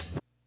hihat01.wav